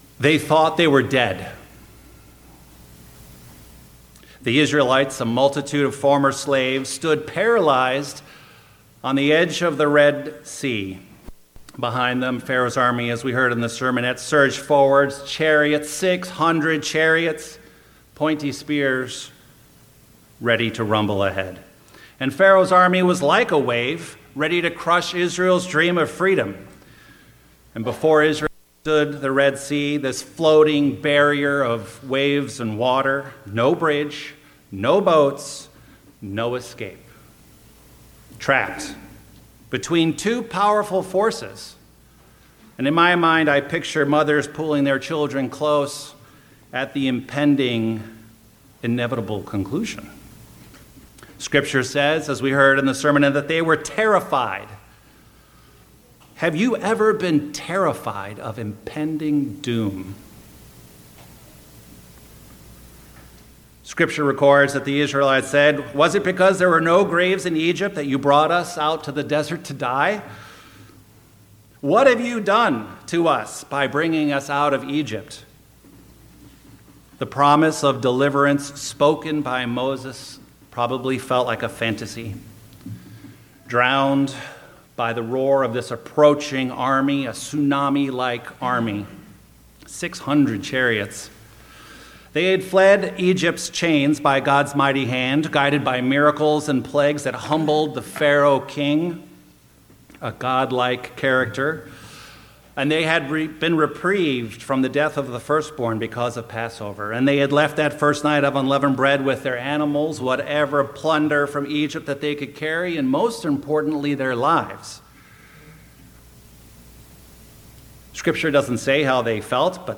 Sermons
Given in Chicago, IL Northwest Indiana